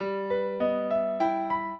piano
minuet12-10.wav